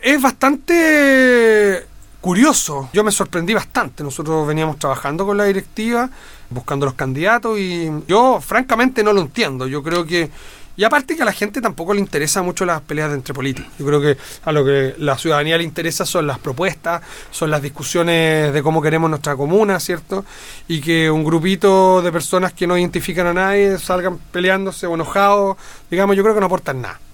Lo anterior fue expuesto por el mismo candidato, en una entrevista realizada este jueves en el estudio de Radio Bío Bío.